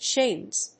/ʃemz(米国英語), ʃeɪmz(英国英語)/